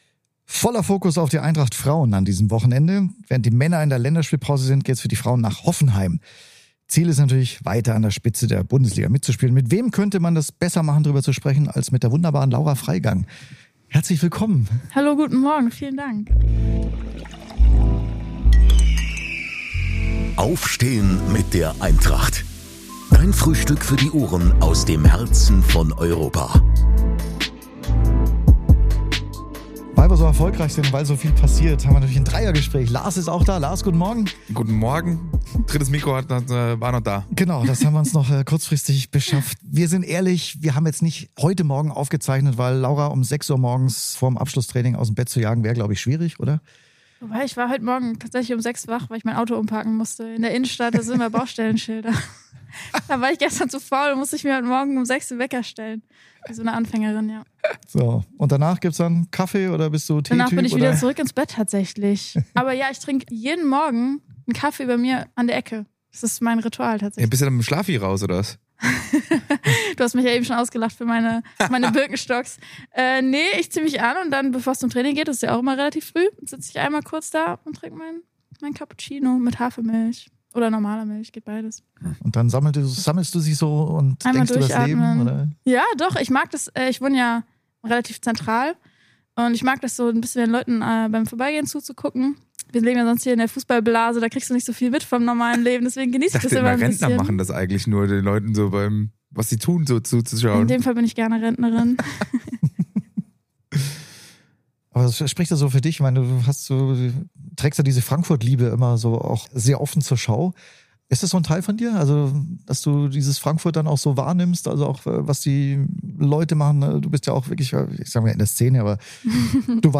ist es ein Gespräch über das Leben mitten in Frankfurt geworden, über Fotografie, Pizza-Prämien für Tore, die Liebe zur Taktik mit der Raute und auch über die Sehnsucht, mit der Eintracht einen Titel zu holen.